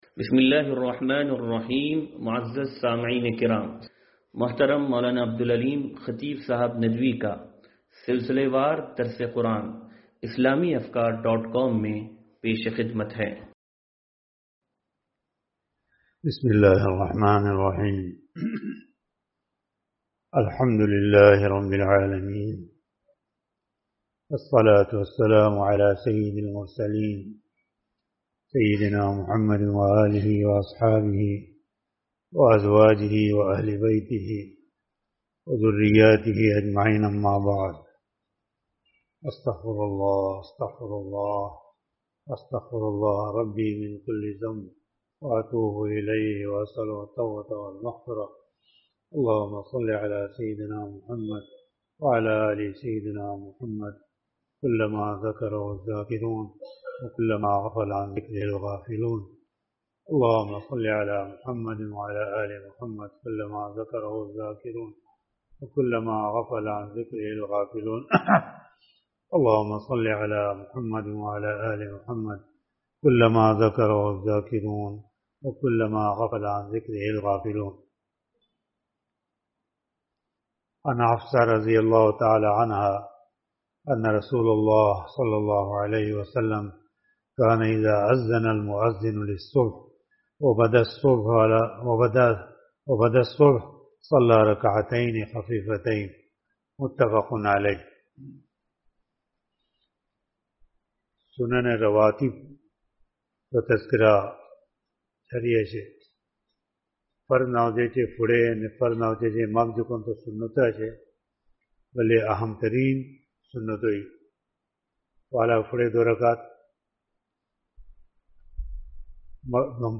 درس حدیث نمبر 0828